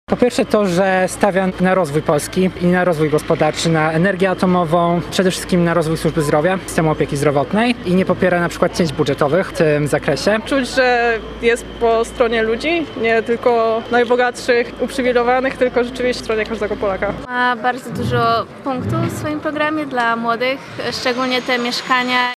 Na spotkaniu pojawiło się dużo młodych zwolenników Adriana Zandberga, którzy tłumaczyli, dlaczego przekonuje ich program kandydata: